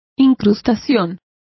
Also find out how incrustación is pronounced correctly.